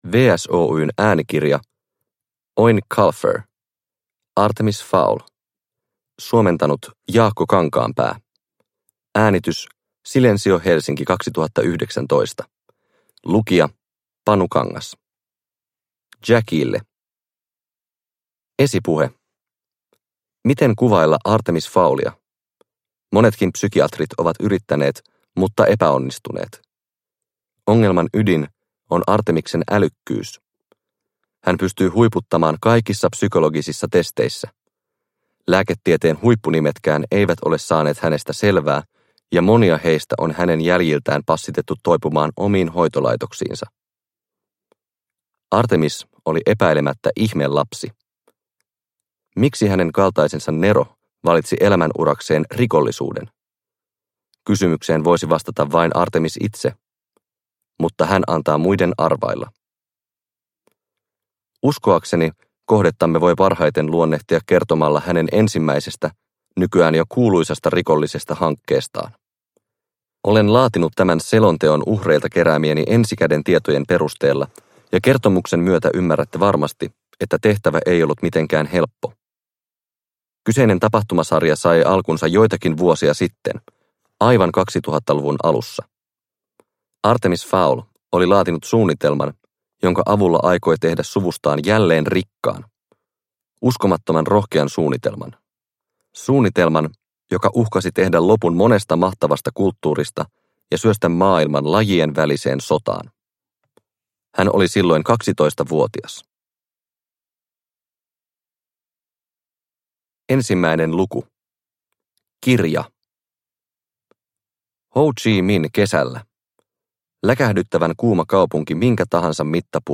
Artemis Fowl – Ljudbok – Laddas ner